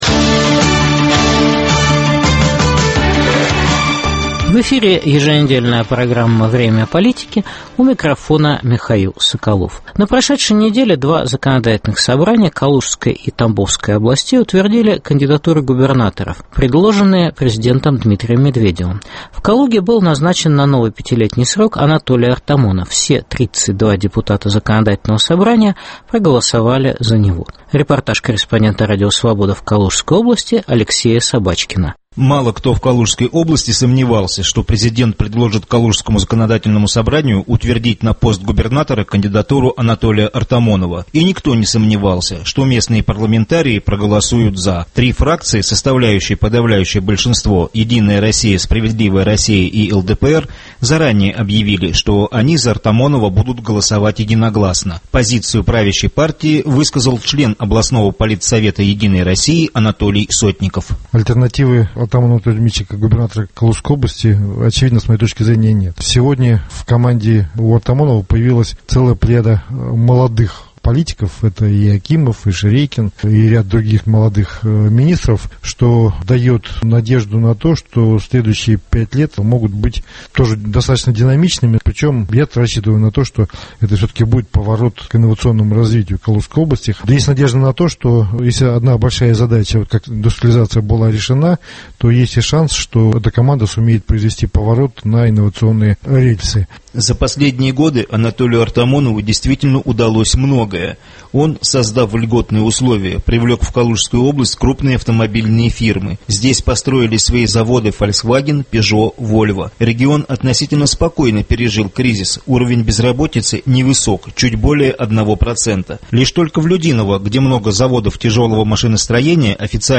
Репортаж из Читы.